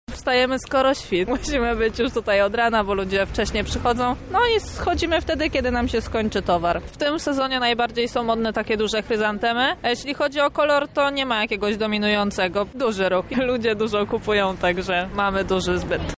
mówi jedna ze sprzedawczyń